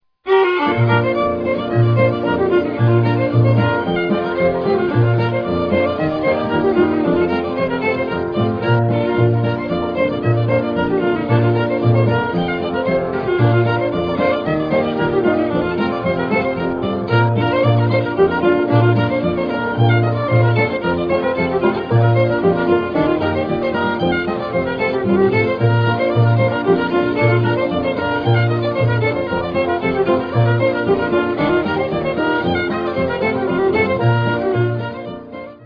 finger busting Midwestern hornpipes